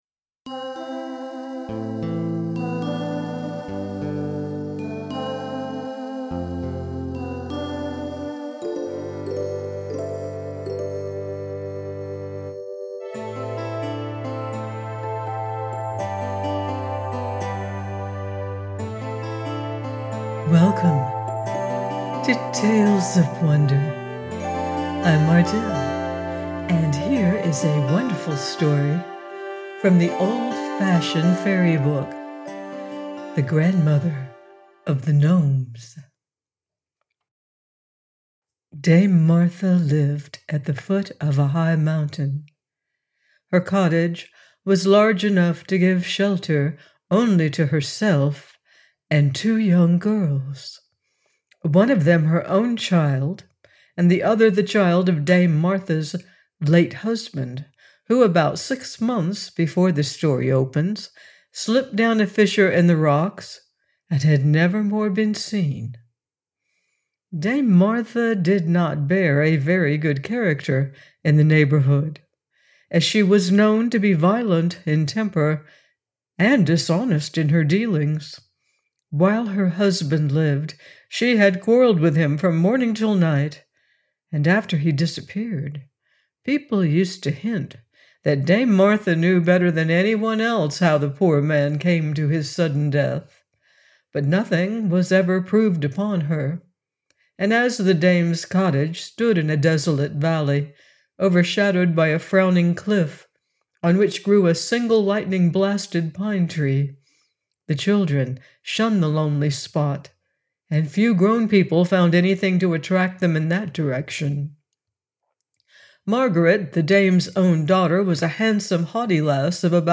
THE GRANDMOTHER OF THE GNOMES - audiobook